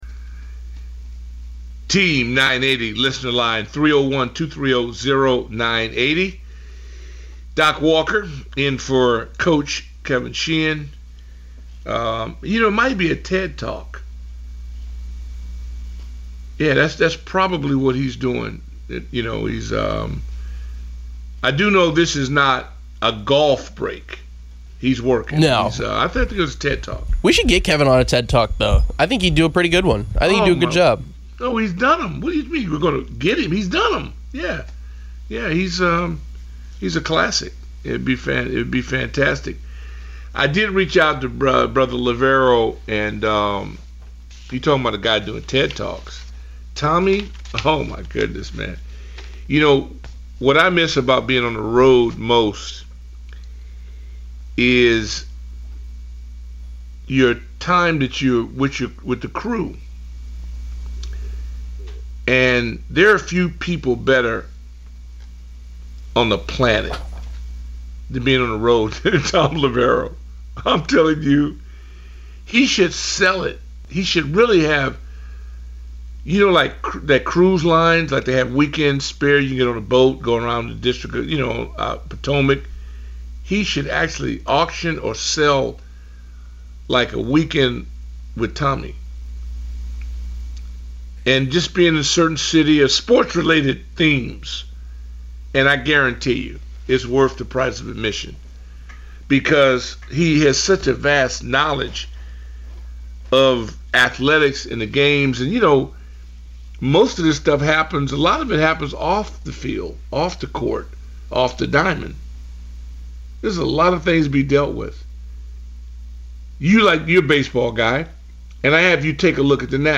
callers talk about the Washington Commanders and NFL news.